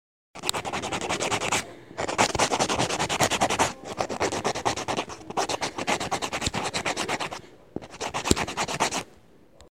Rayando efusivo con un bolígrafo
Grabación sonora que capta el sonido de presión y rayado que hace un bolígrafo al ser presionado contra una superficie al pintar (en un folio, cartulina...) de manera repetida.
Sonidos: Acciones humanas